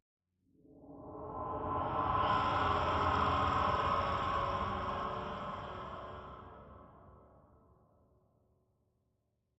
Minecraft Version Minecraft Version 1.21.5 Latest Release | Latest Snapshot 1.21.5 / assets / minecraft / sounds / ambient / nether / crimson_forest / mood3.ogg Compare With Compare With Latest Release | Latest Snapshot